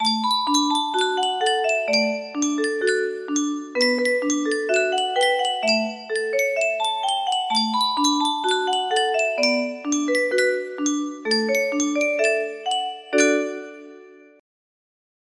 Yunsheng Music Box - Unknown Tune 1070 music box melody
Full range 60